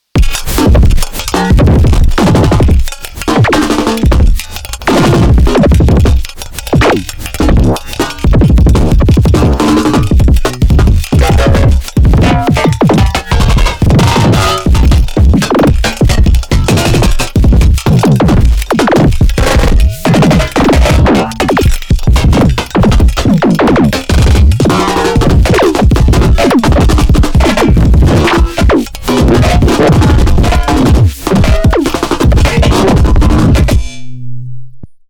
No that’s just me switching randomly between 3 or 4 scenes with repeats and accumulators aux events.
I only used Bitwig devices for the sounds.